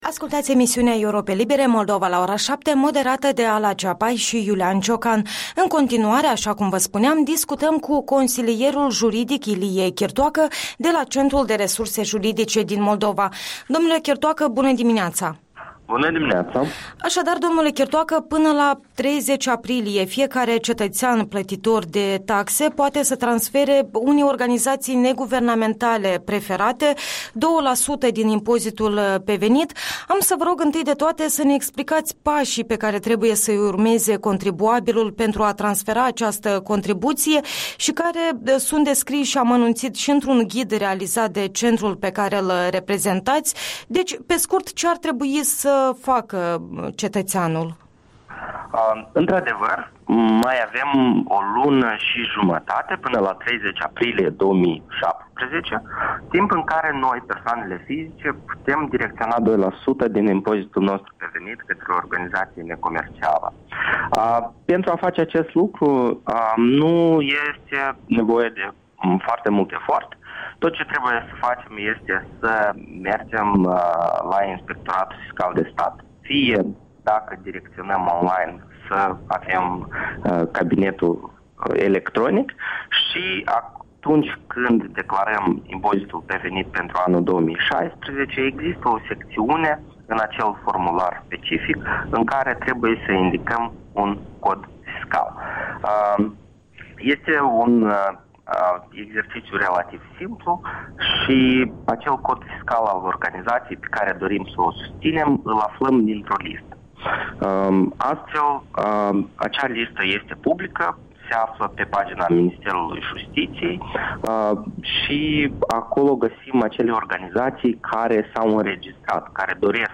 Interviul dimineții cu un consilier juridic de la Centrul de Resurse Juridice din Moldova.